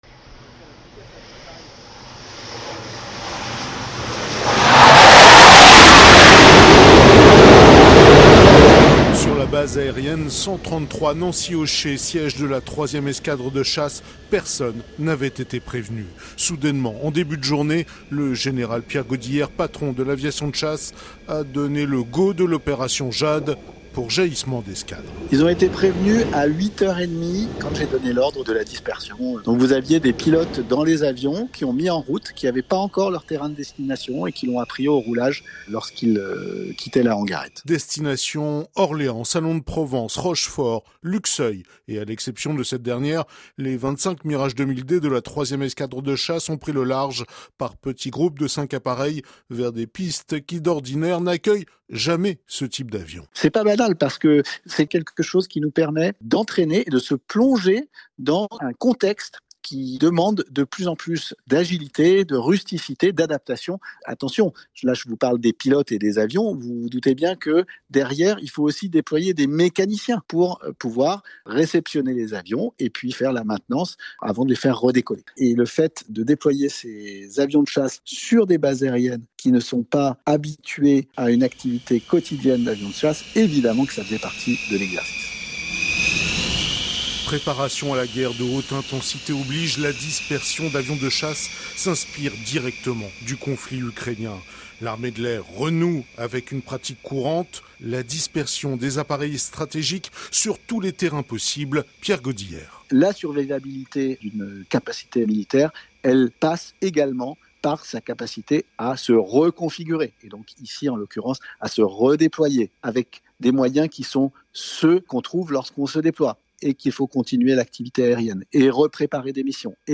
Essaims de drones, drones suicides, capacité de cartographie, l’exercice avait pour objectif de démontrer la montée en puissance des drones dans les forces. Reportage